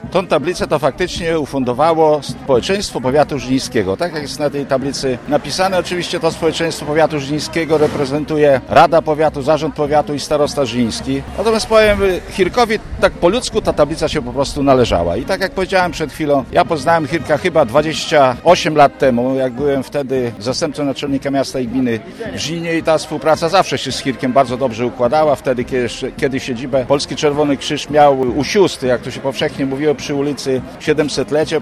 Mówił starosta żniński Zbigniew Jaszczuk.